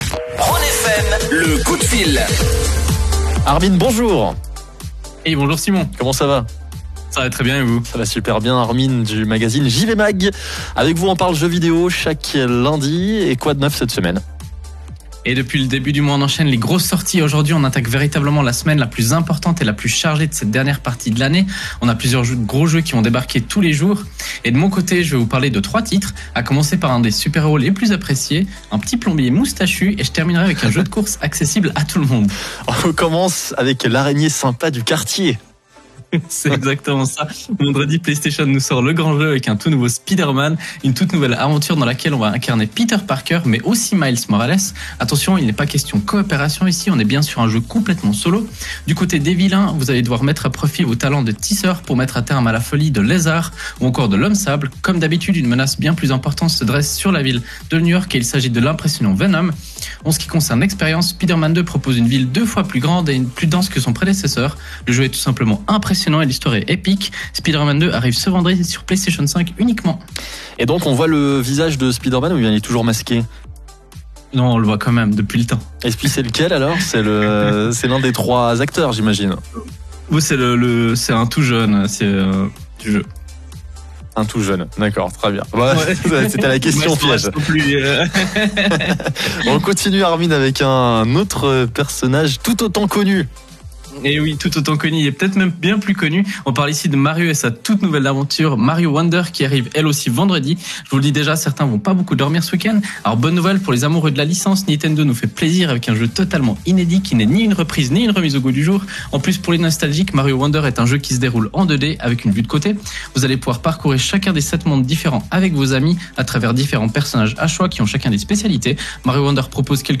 C’est d’ailleurs le sujet de notre chronique gaming hebdomadaire sur la radio Rhône FM.